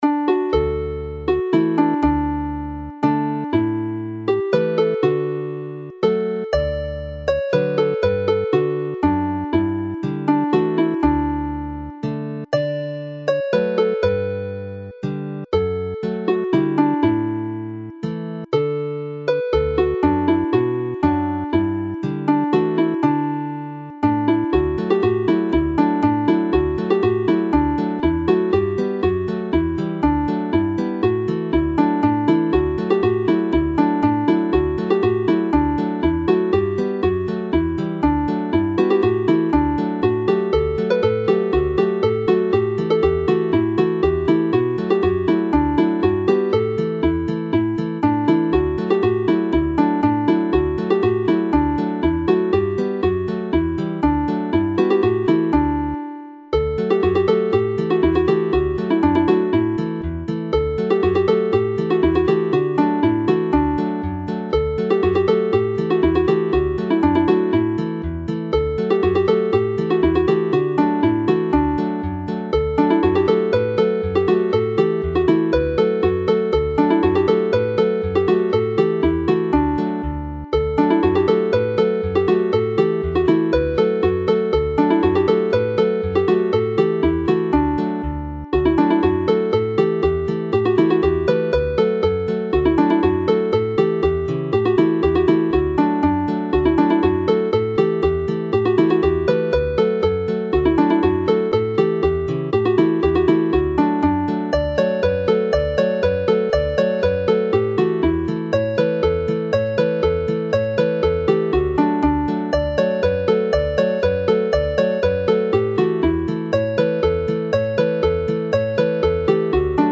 Ceiliog Du set - pipe tunes
Mi Glywaf Dyner Lais (I hear a tender voice) is a lovely, lyrical Welsh hymn tune and the other two are straight pipe tunes which are within the single octave range of the bagpipe and the pibgorn.